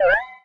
low_health_beep_02.ogg